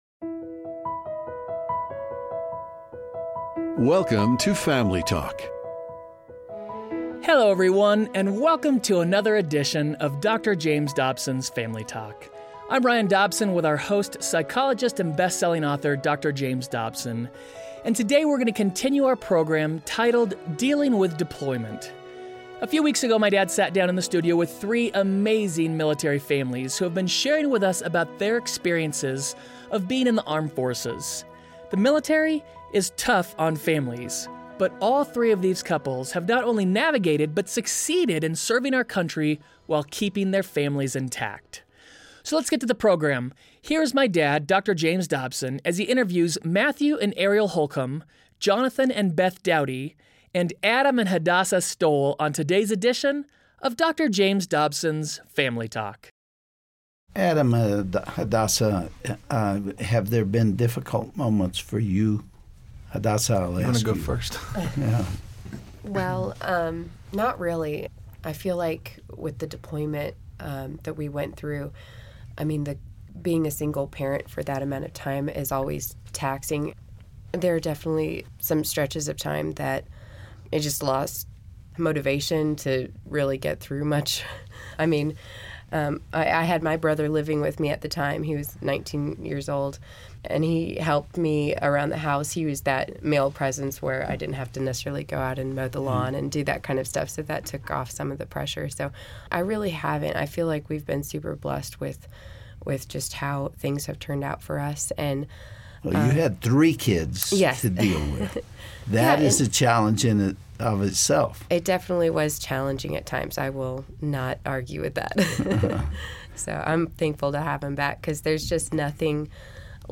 With moving, deployments, and hard experiences, how can marriage and family be done well? Dr. James Dobson continues his interview with 3 military couples about their stance for faith and family.